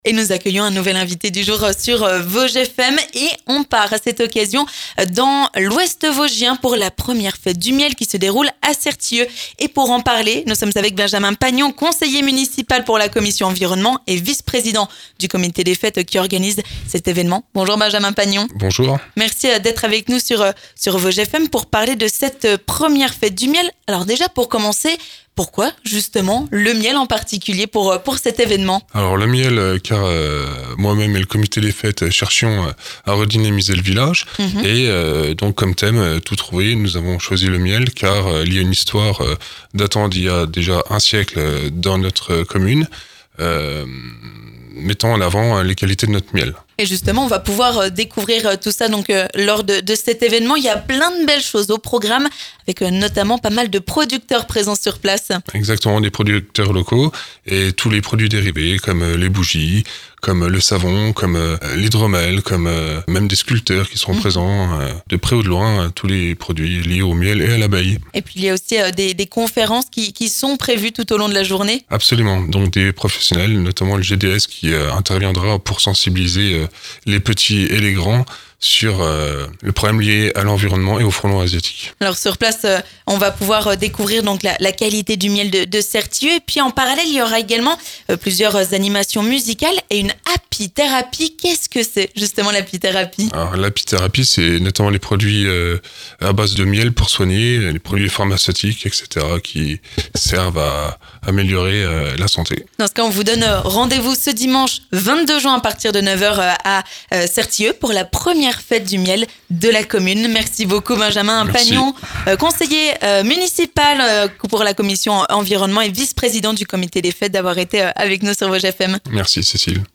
Découvrez sur place des producteurs locaux, mais également des conférences sur la préservation de ses insectes pollinisateurs ou encore sur la lutte contre le frelon asiatique. On vous explique tout avec notre invité du jour, Benjamin Pagnon, conseiller municipal de Certilleux à la commission environnement et vice-président du Comité des Fêtes.